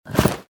ui_interface_70.wav